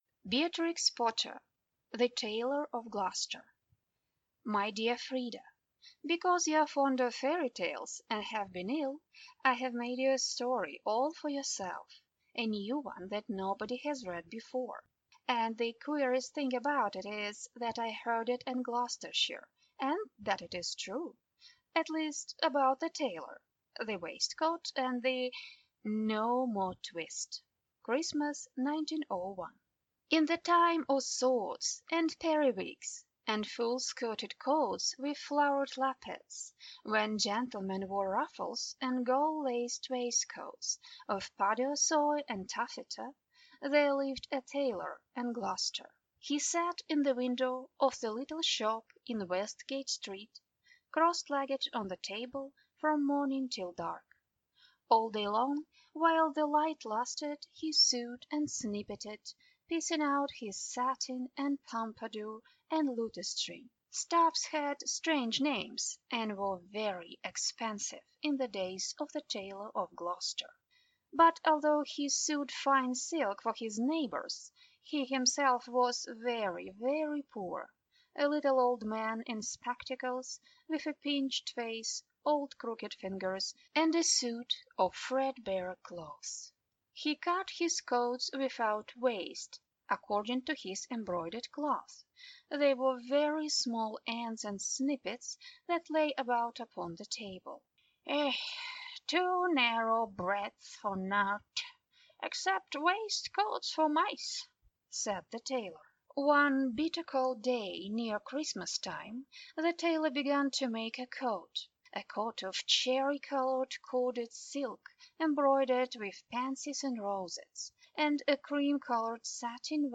Аудиокнига The Tailor of Gloucester | Библиотека аудиокниг